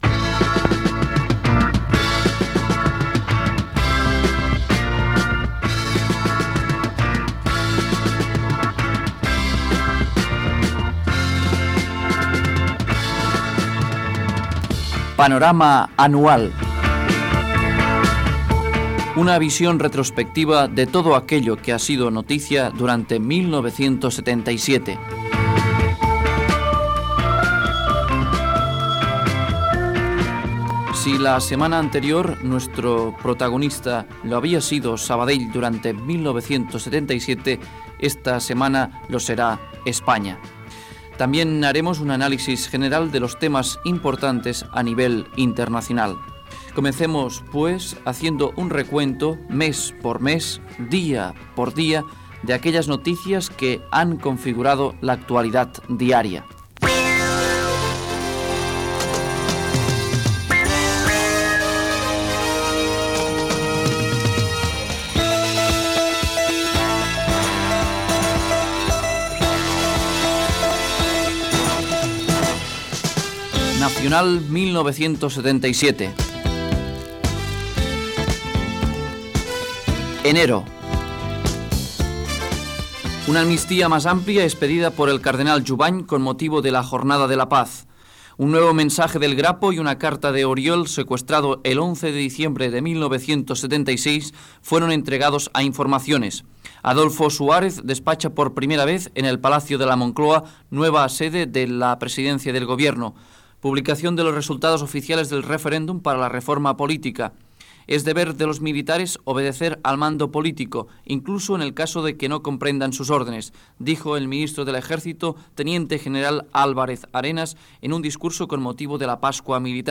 Presentació del programa, resum de l'actualitat de l'any 1977 a l'Estat espanyol Gènere radiofònic Informatiu